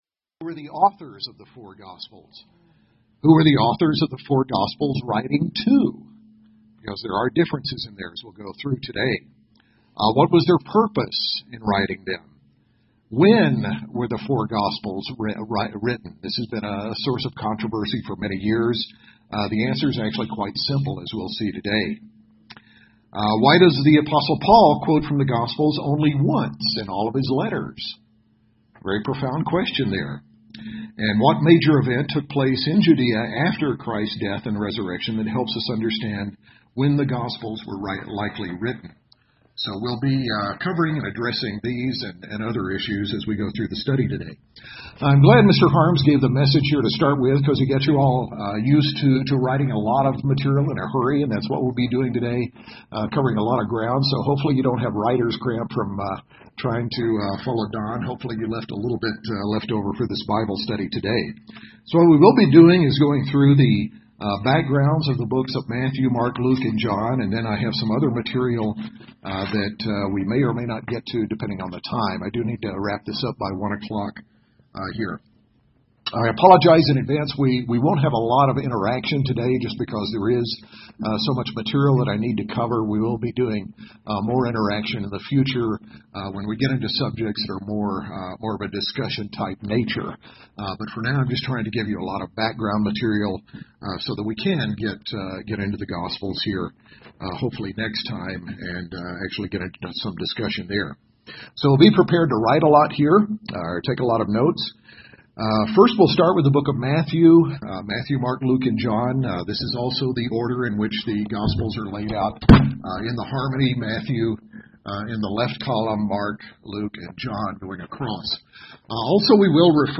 In this Bible class we examine the author, intended audience, purpose, structure and key elements of the four Gospels of Matthew, Mark, Luke and John.